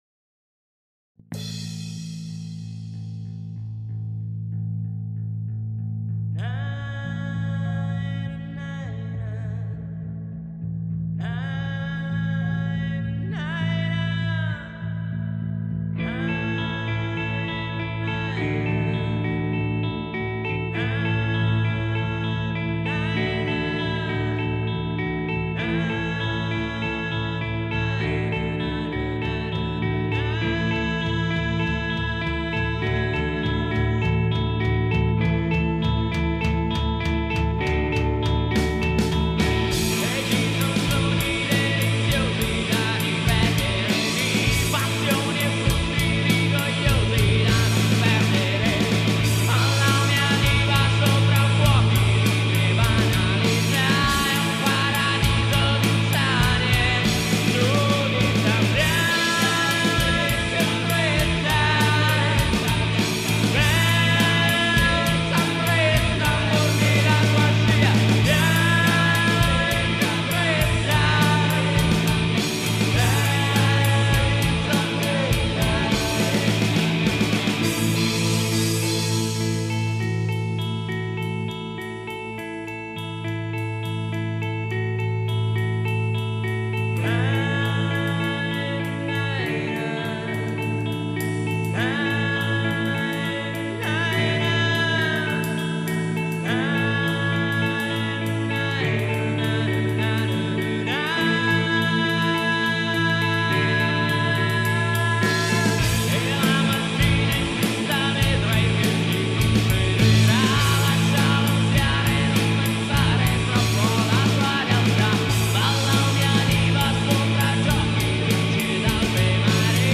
Il loro è un Alternative Rock melodico